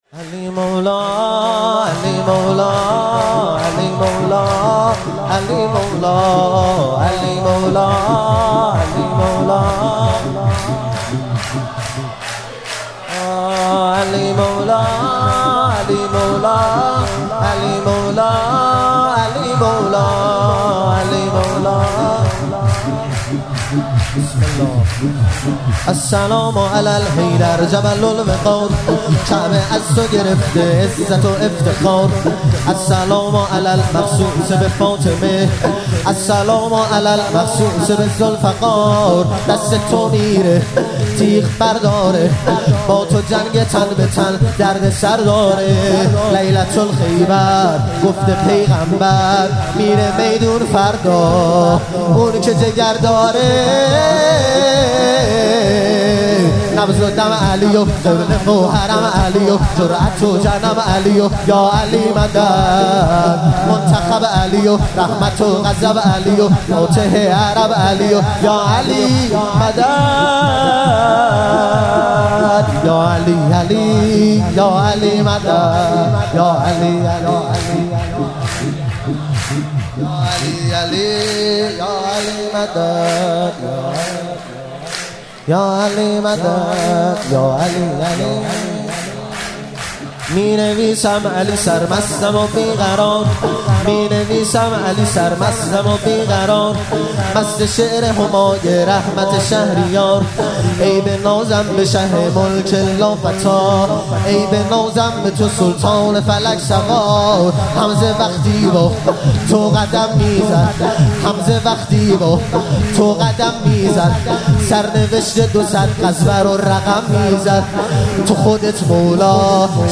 مراسم جشن سالگرد ازدواج حضرت امیرالمومنین علی علیه السلام و حضرت فاطمه زهرا سلام الله علیها- خرداد 1402
شور- السلام علی حیدر جبل الوقار